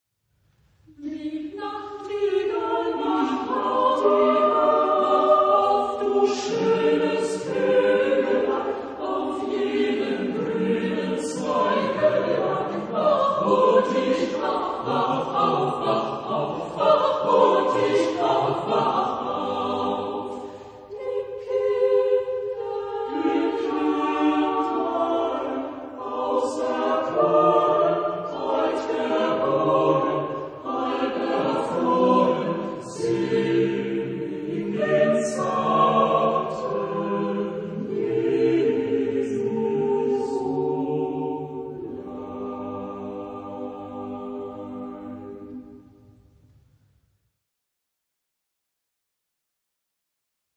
Type of Choir: SATB  (4 mixed voices )
Tonality: G major